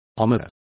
Complete with pronunciation of the translation of armourer.